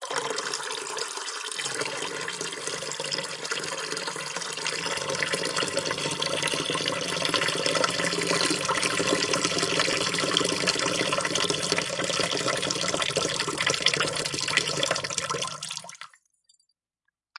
描述：用吸管将泡沫吹成塑料杯水。使用索尼IC录音机录制，在FL Studio的爱迪生处理，以消除噪音。
标签： 气泡 饮料 稻草 吹制 索尼集成电路（IC）记录器
声道立体声